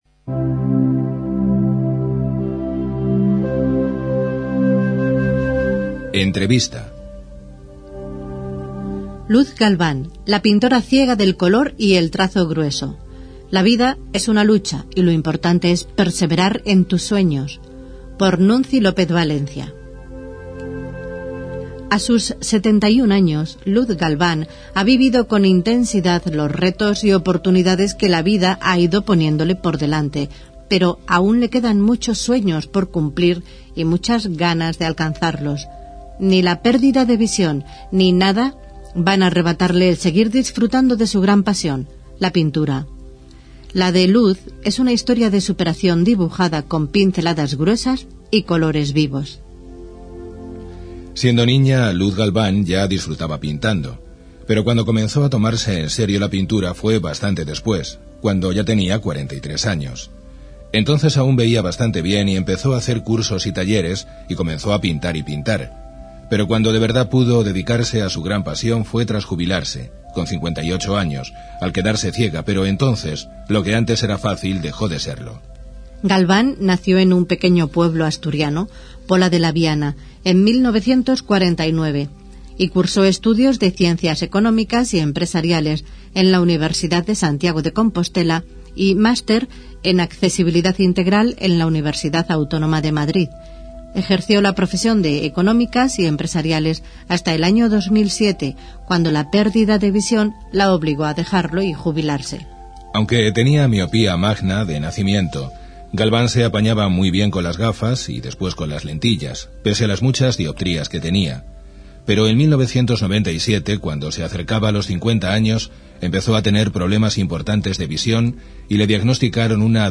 05 ENTREVISTA_0.mp3